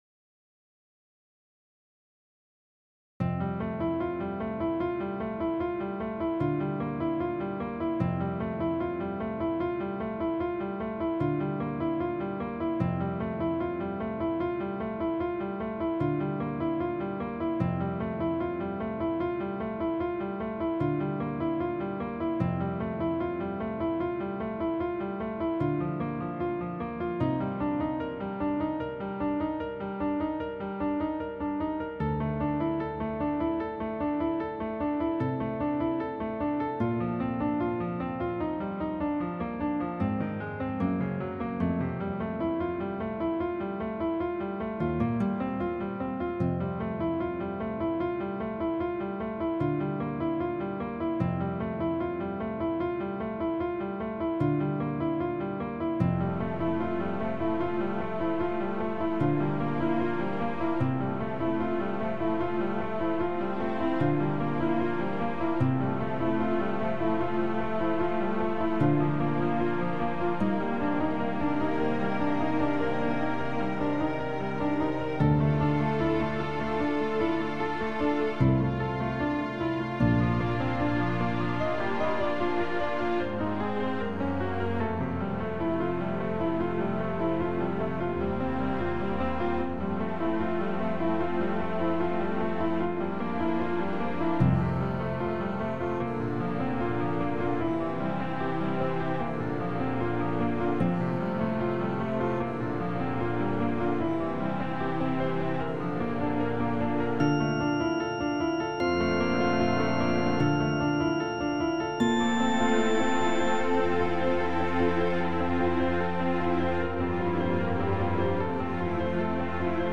自制karaoke
非消音，听写的。